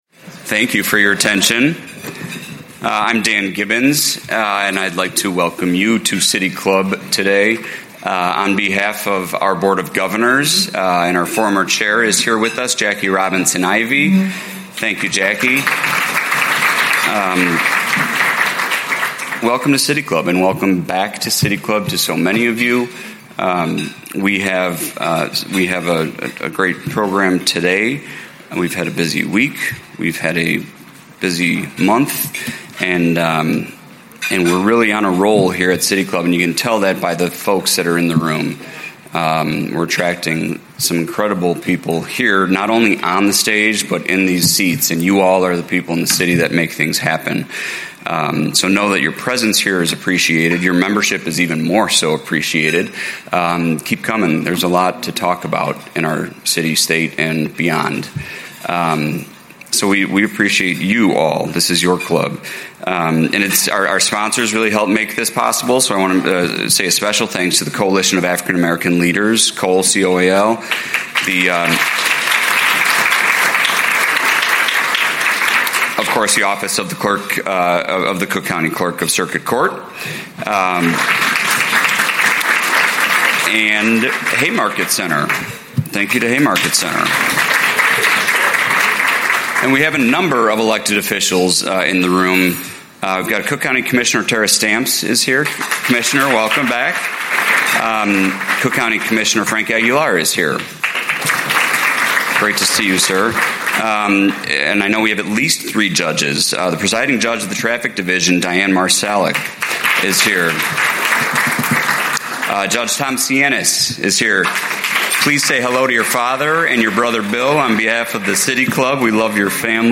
City Club of Chicago: Clerk of the Circuit Court of Cook County, Mariyana Spyropoulos